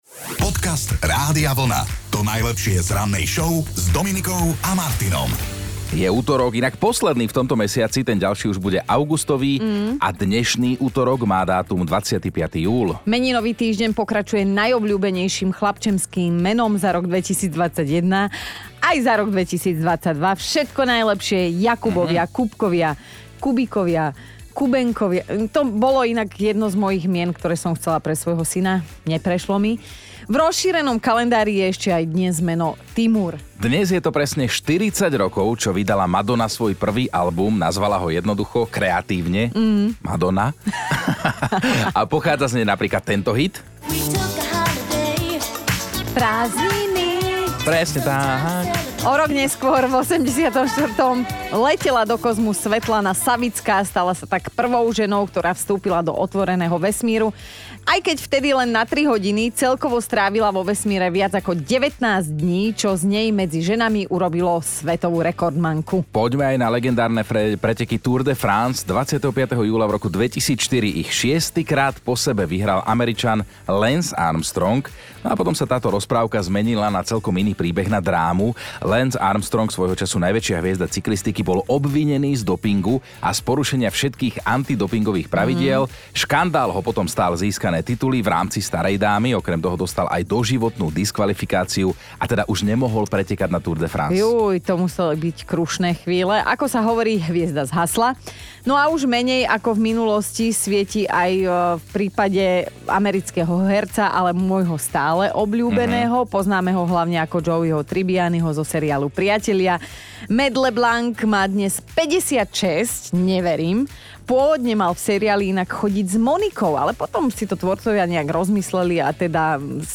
To najlepšie z vysielania rádia Vlna.